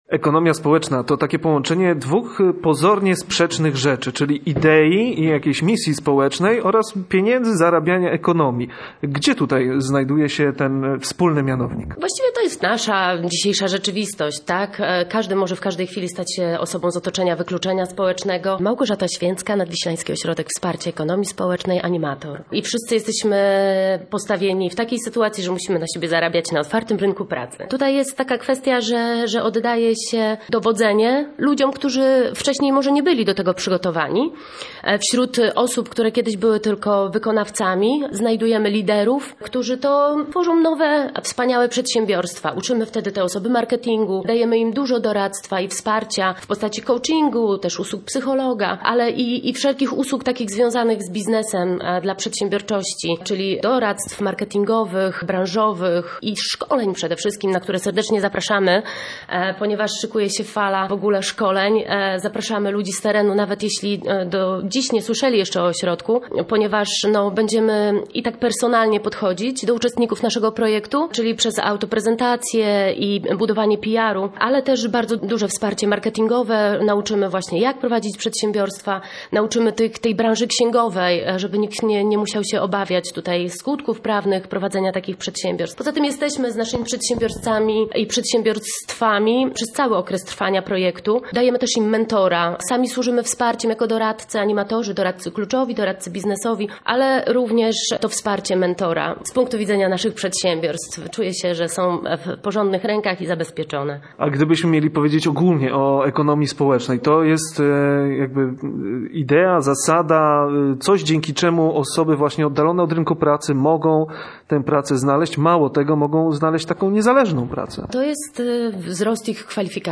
Tym razem zapraszamy na Żuławy, gdzie w Dzierzgoniu funkcjonuje Nadwiślański Ośrodek Wsparcia Ekonomii Społecznej.